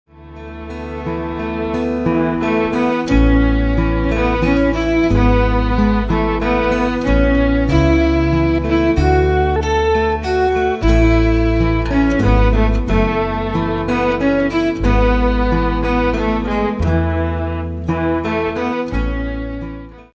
chitarra acustica.